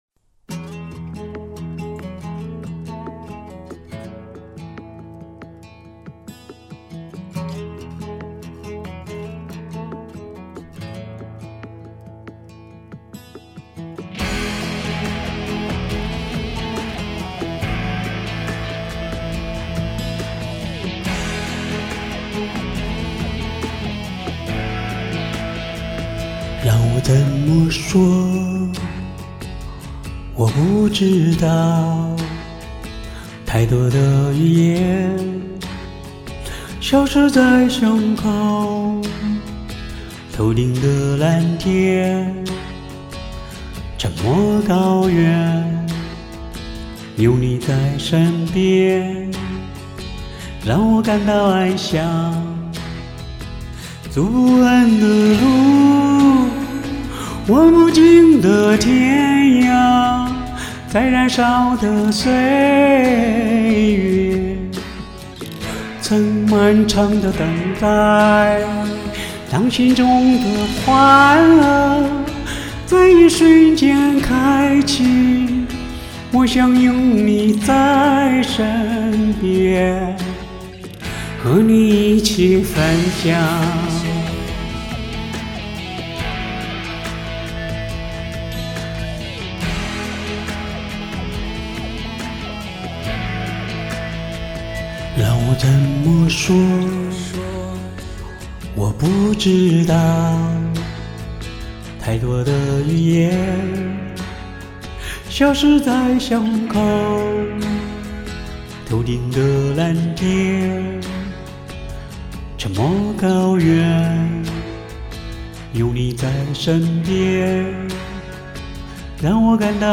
翻唱